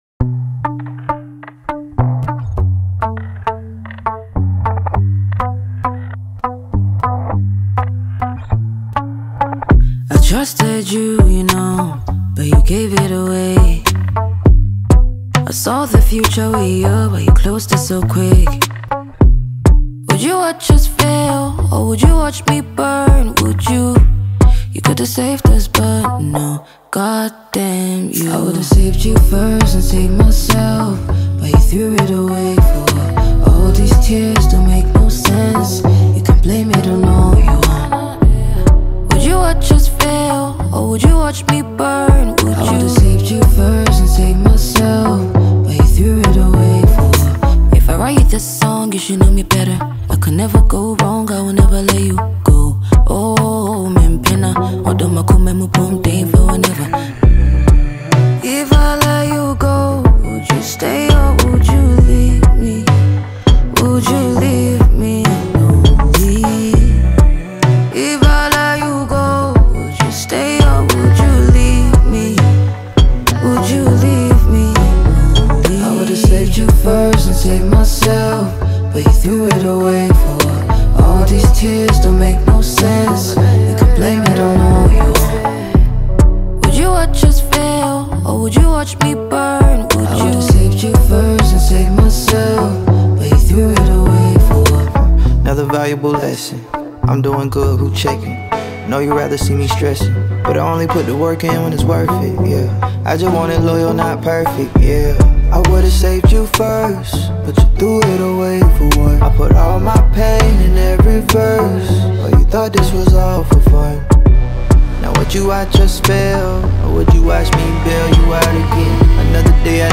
Talented Ghanaian female singer and songwriter